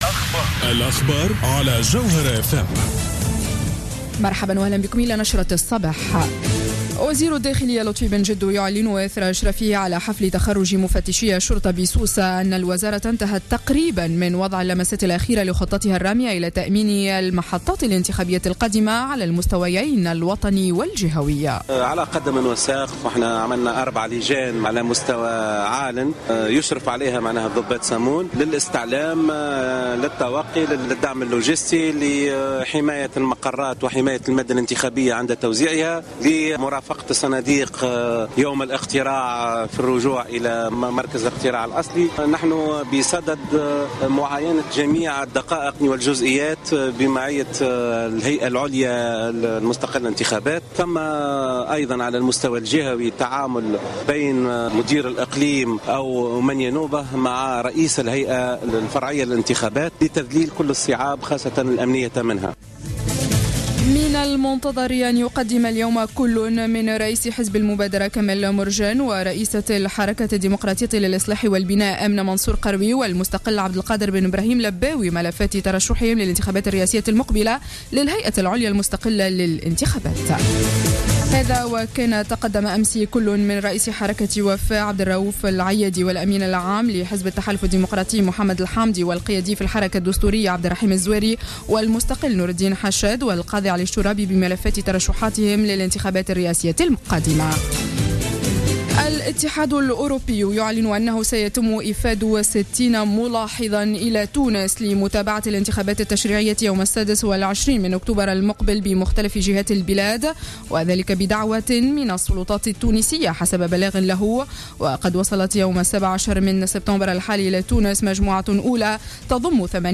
نشرة أخبار السابعة صباحا ليوم السبت 20-09-14